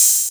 Open Hats
MURDA_HAT_OPEN_CHIRON.wav